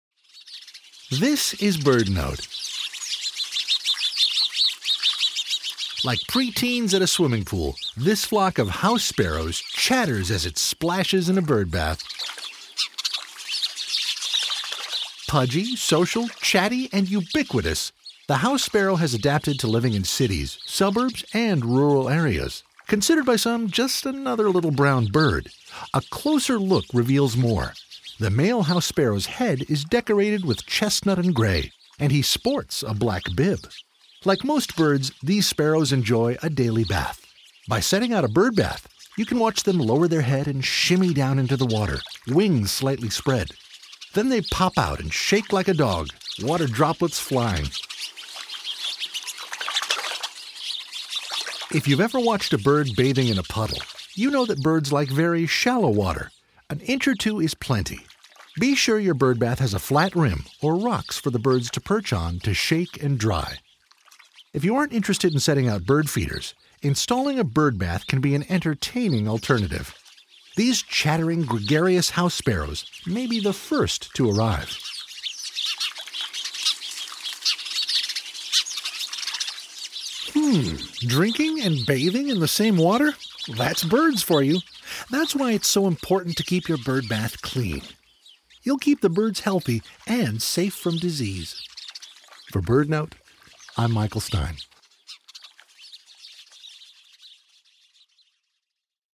Social, chatty, ubiquitous, the House Sparrow has adapted to living in cities, suburbs, and rural areas. Like most birds, these sparrows enjoy a daily bath. Set out a birdbath, and you can watch them chatter, splash, and shake, sending droplets flying.